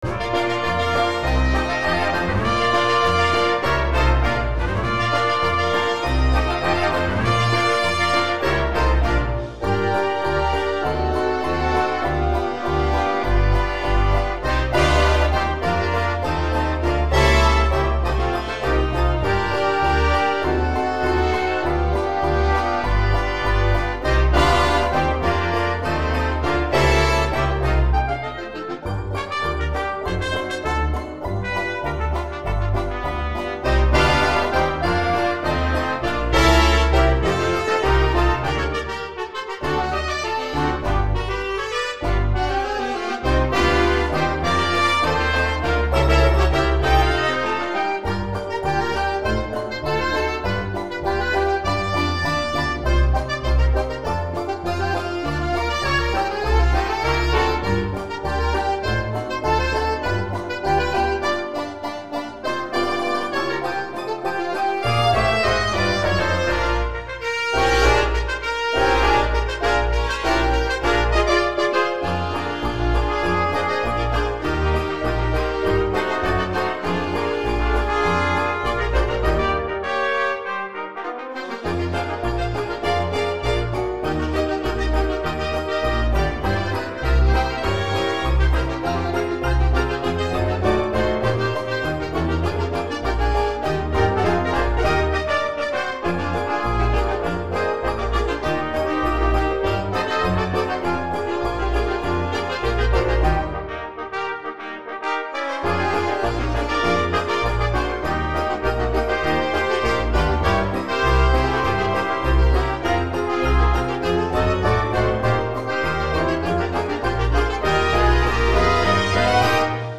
Early jazz orchestra